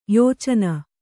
♪ yōcana